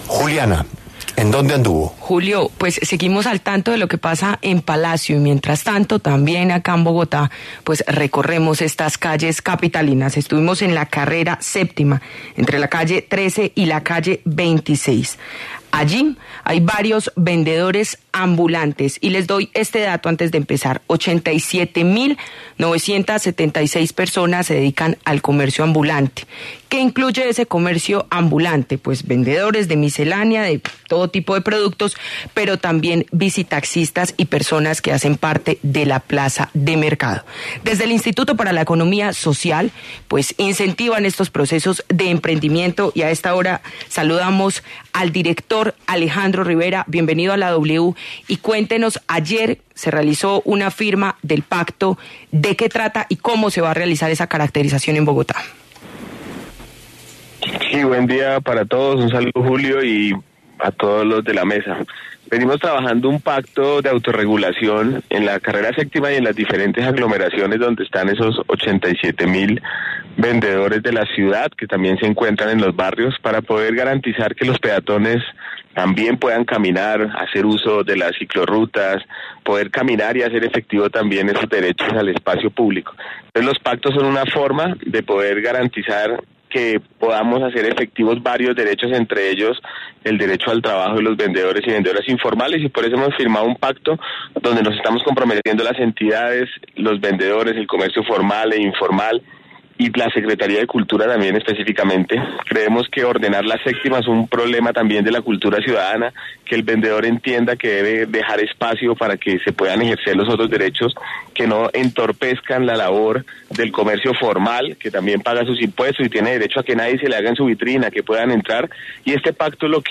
Alejandro Rivera, director del Instituto para la Economía Social, explicó en los micrófonos de La W las estrategias que tomarán para regular la venta ambulante en esta zona de la capital del país.